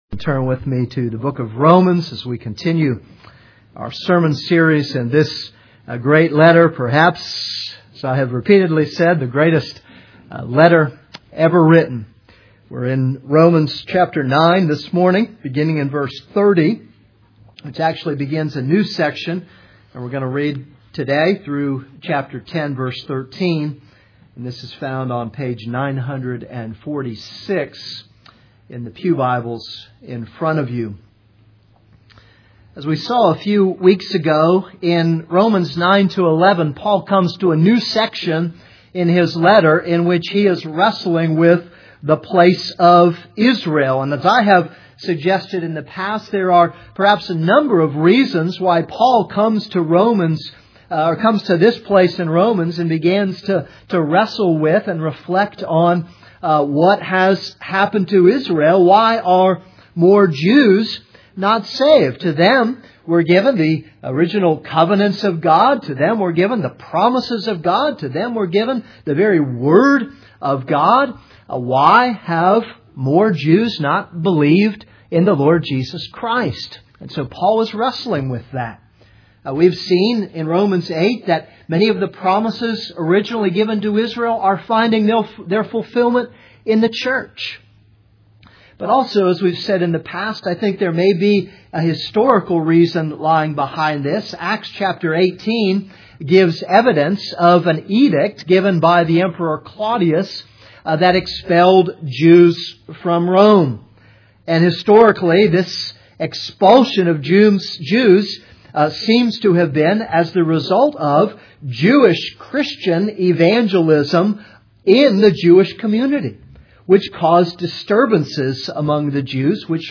This is a sermon on Romans 9:30-10:13.